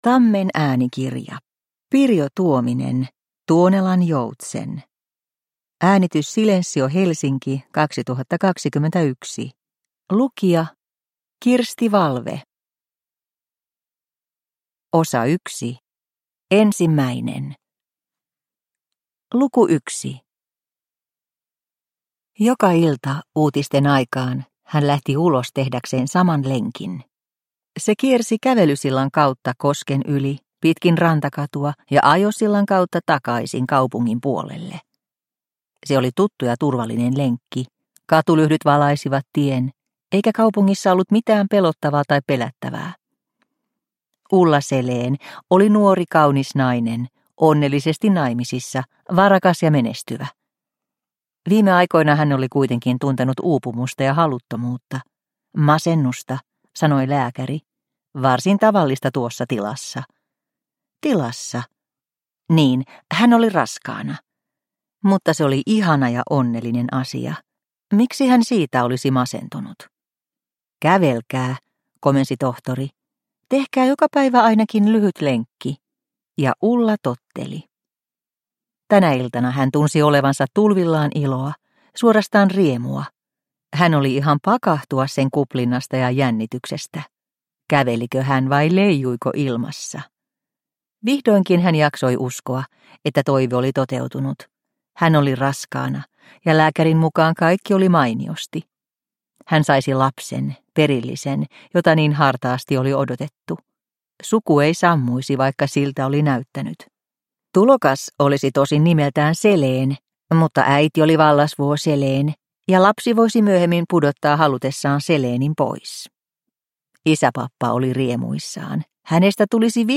Tuonelan joutsen – Ljudbok – Laddas ner